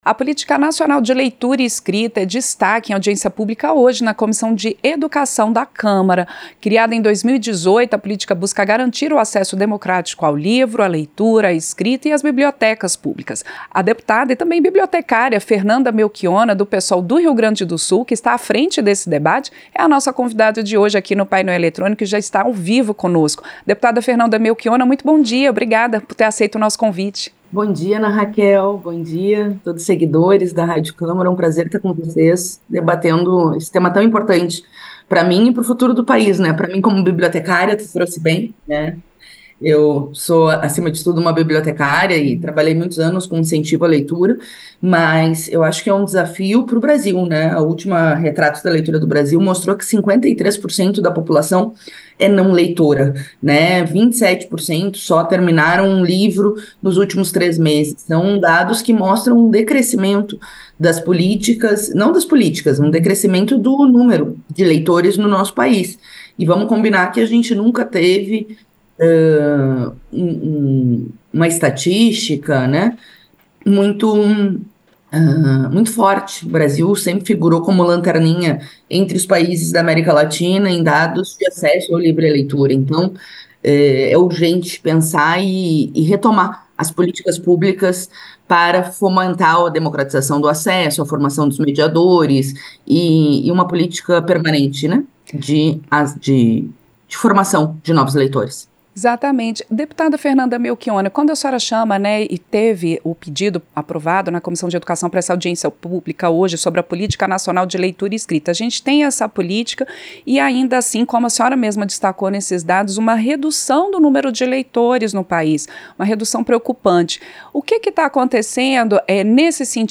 Entrevista - Dep. Fernanda Melchionna (PSol-RS)